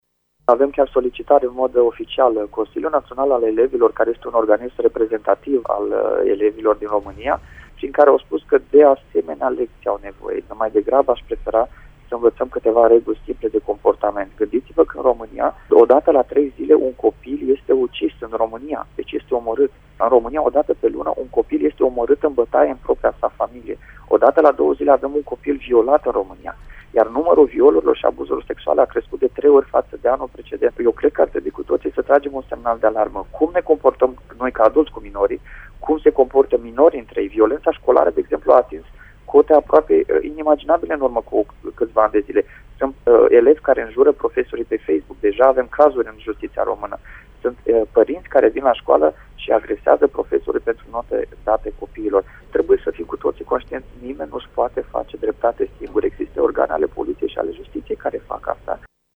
Se bucură de succes astfel de lecții. Magistratul CSM: